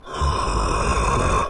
Zombie groans » Groan 1
描述：Zombie groans
标签： brains dead zombie
声道立体声